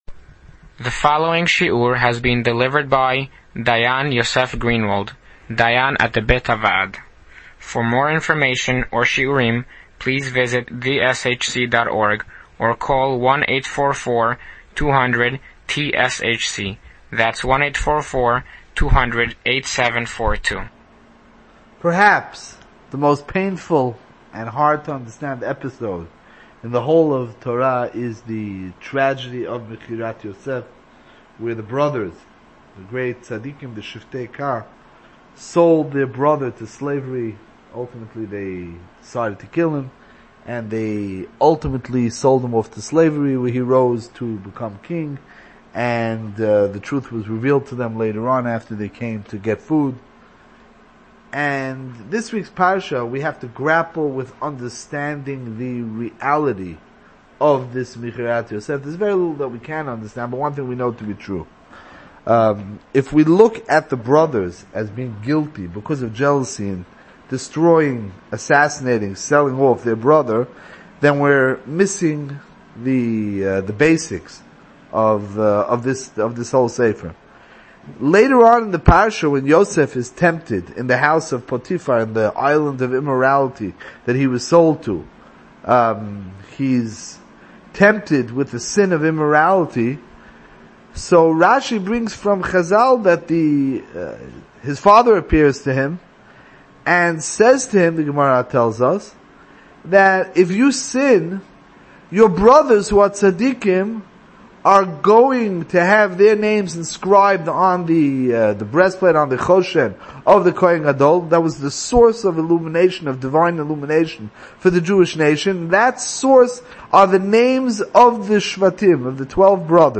A Parasha & Halacha Shiur